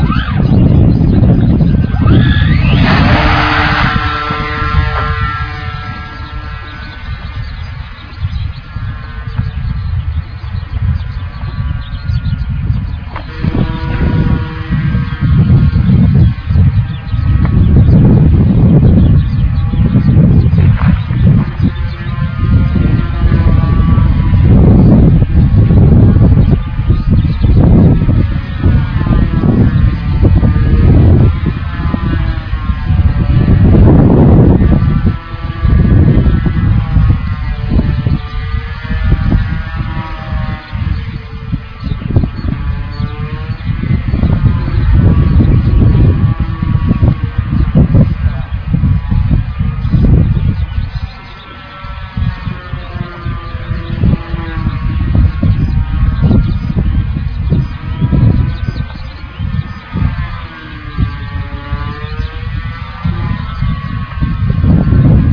400クラスの電動機。
ループ、ロール、スプリットSなどをこなす。急上昇時にはモータ音が変わるのがわかる。
エスパー S/400の飛行ビデオ